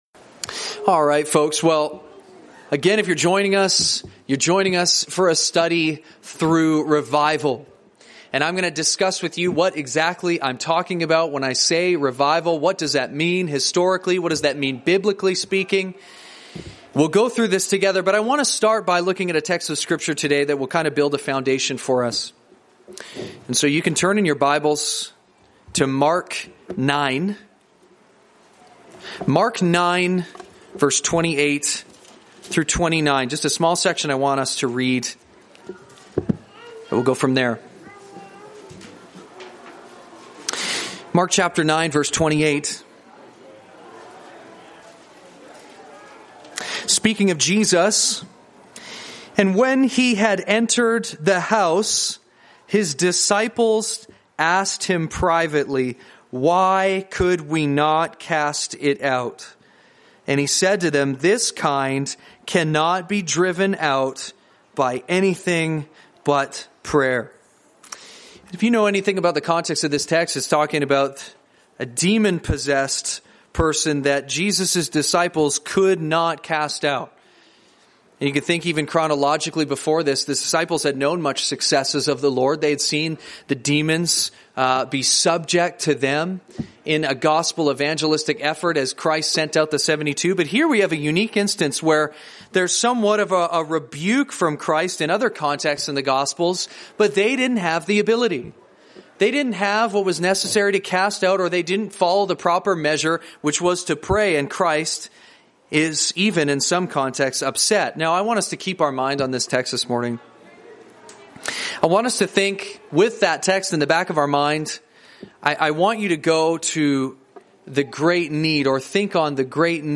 Sunday Seminar on revival.